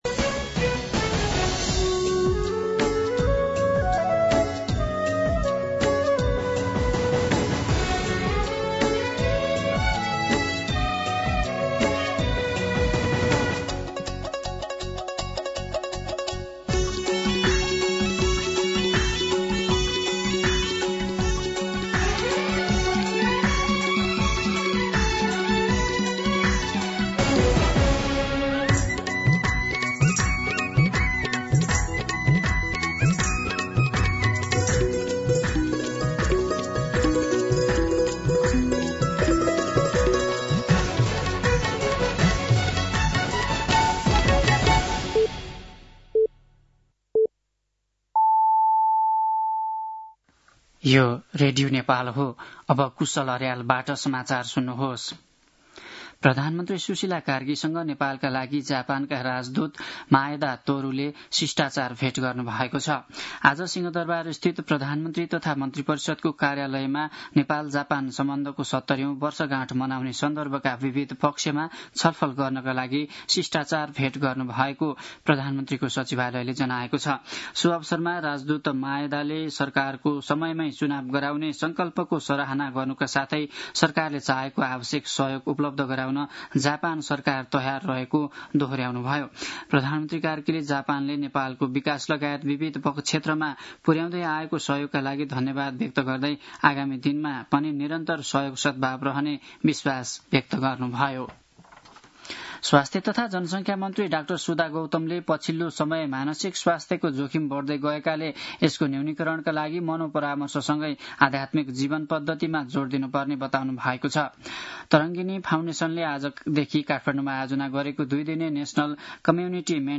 दिउँसो ४ बजेको नेपाली समाचार : १९ मंसिर , २०८२